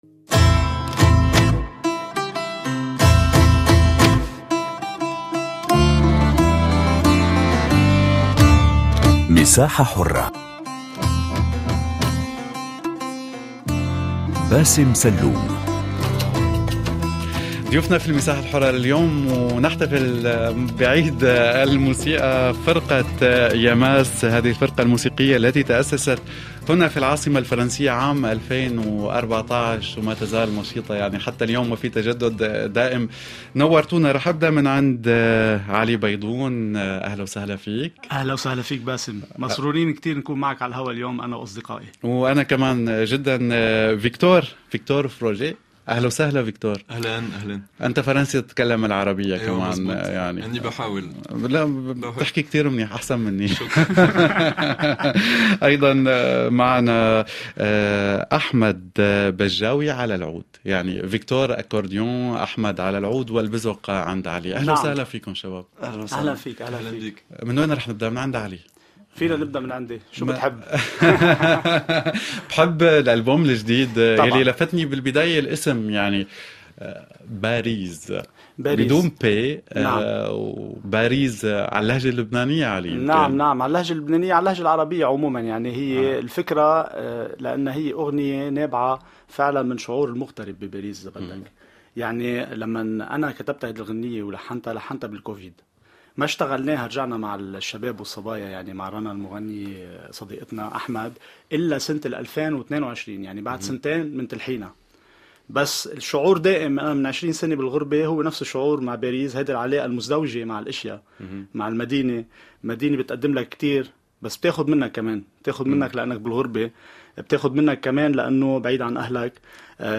مجلة صباحية يومية يلتقي فيها فريق كافيه شو مع المستمعين للتفاعل من خلال لقاءات وفقرات ومواضيع يومية من مجالات مختلفة : ثقافة، فنون، صحة، مجتمع، بالاضافة إلى الشأن الشبابي عبر مختلف بلدان العالم العربي.